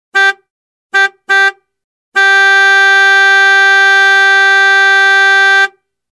Звуки радара